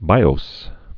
(bīōs)